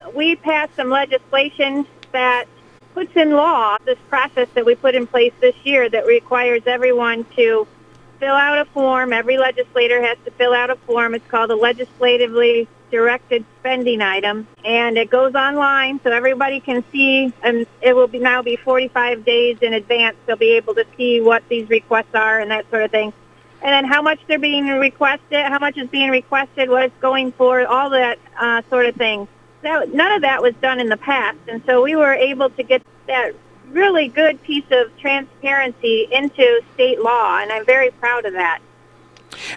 That was State Representative Nancy Jenkins, discussing new transparency requirements for state spending.